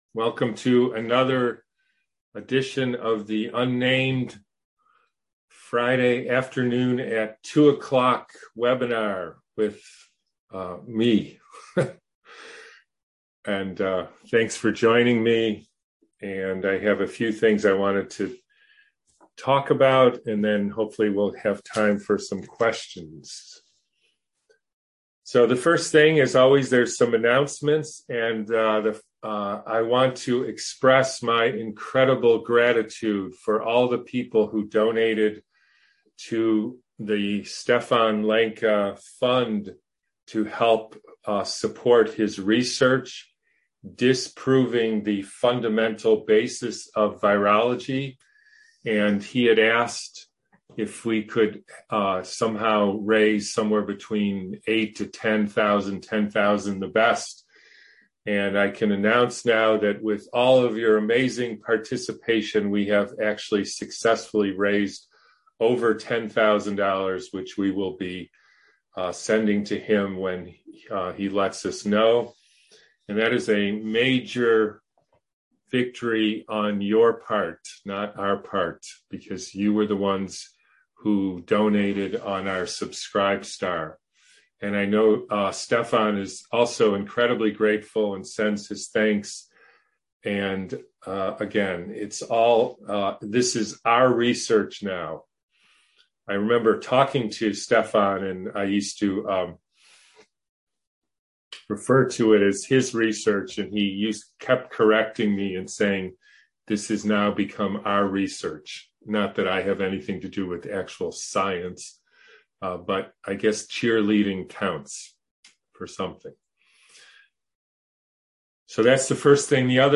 Covid-19 Myths 4 Preview A Response To The Anti-Vax Community- Friday 1112 Webinar